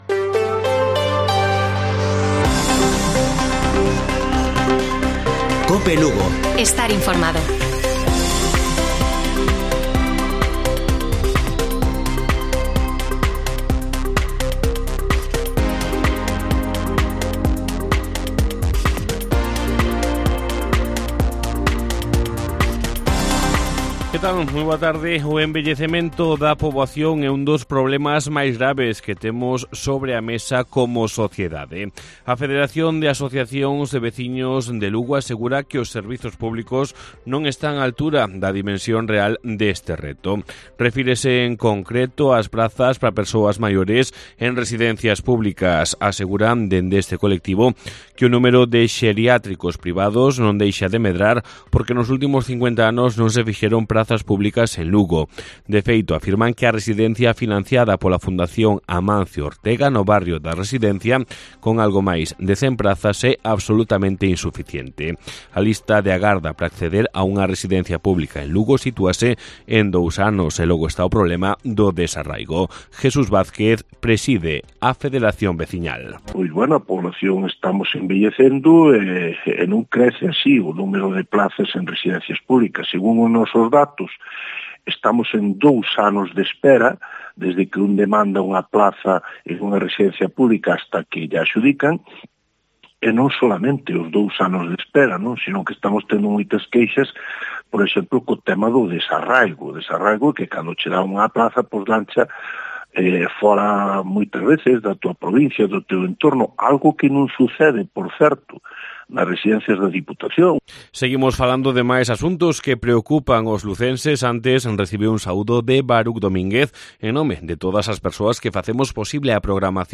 Informativo Mediodía de Cope Lugo. 27 de junio. 13:50 horas